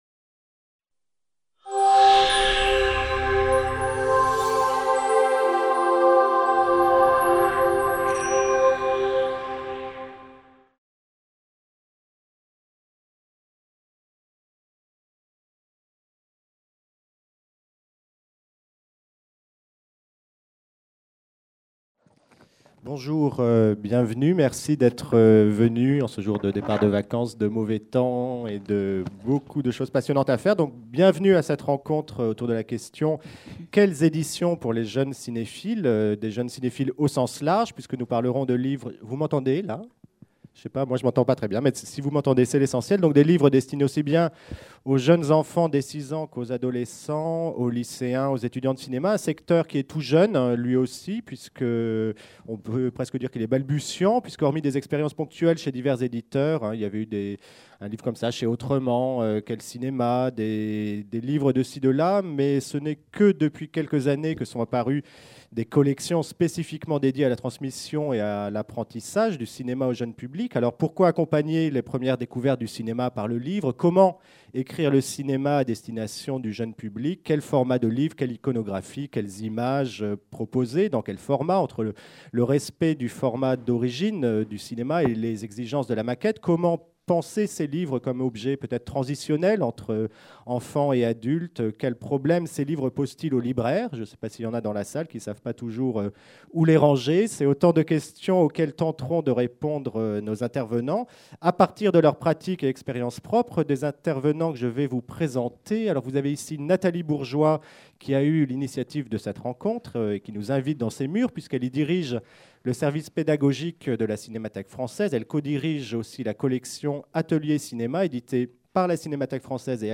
Quelles éditions pour les jeunes cinéphiles ? Table ronde | Canal U
Quelles initiatives en ce sens, comment écrire le cinéma, non pas comme c'est l'usage, pour des adultes, mais pour des enfants et des adolescents ? Quelles perspectives à l'échelle européenne ? Points de vue croisés d'éditeurs, d'auteurs et d'acteurs de l'éducation artistique.